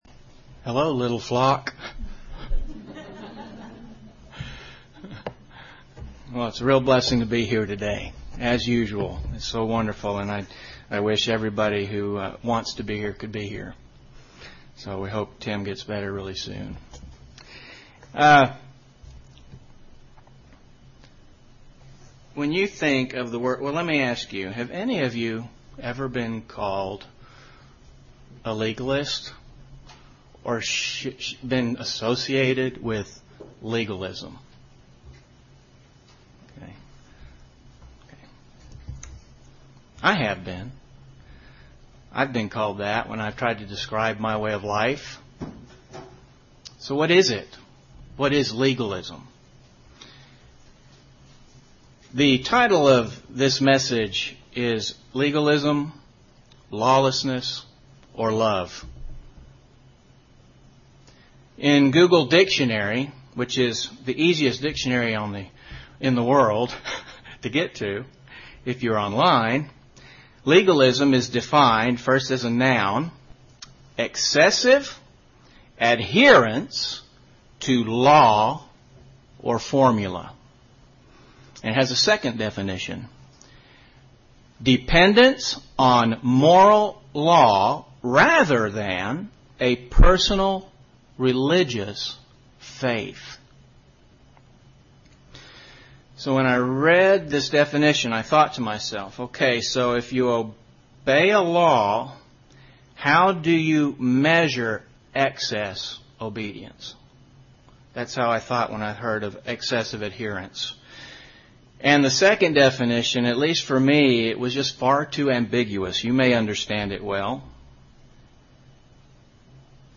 UCG Sermon legalism Grace Faith good works Notes PRESENTER'S NOTES LEGALISM, LAWLESSNESS OR LOVE?